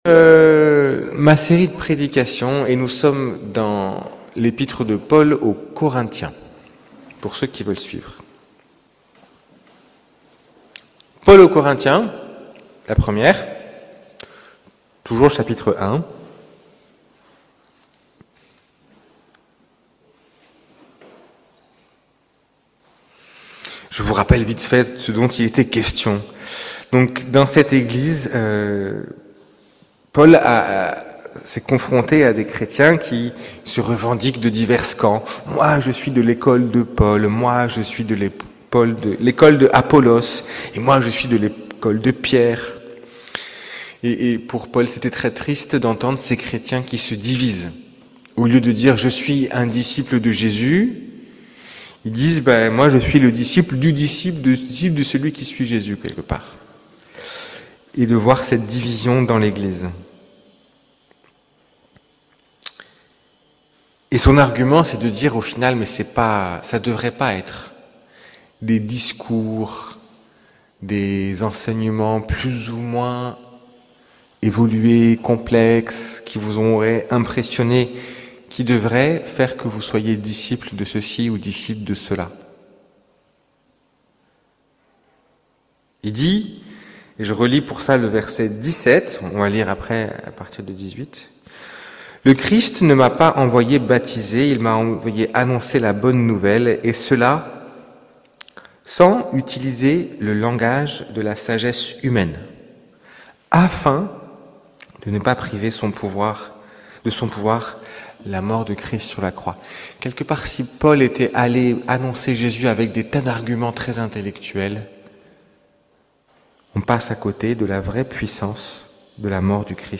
Prédications Audio - Eglise Réformée Evangelique Vauvert